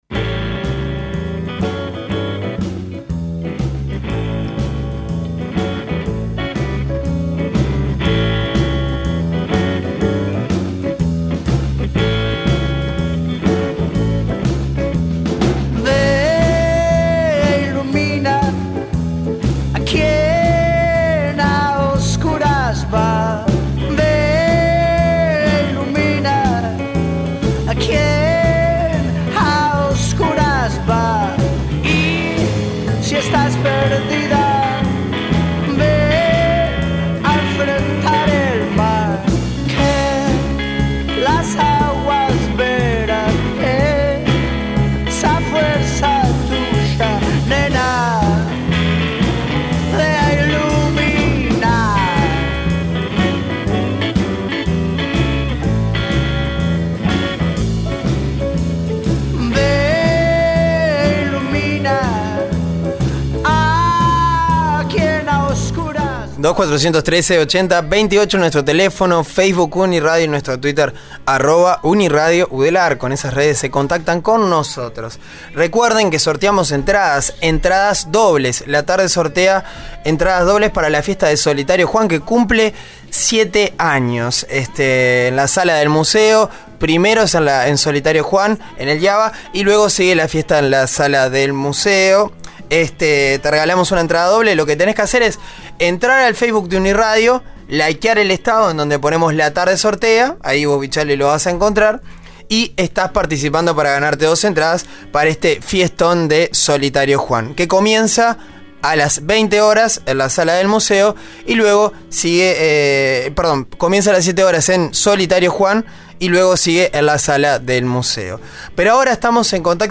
Tuvimos comunicación telefónica con Maxi Prietto, cantante de la banda Los Espíritus, que se estará presentando este viernes en la fiesta del 7mo aniversario de Solitario Juan.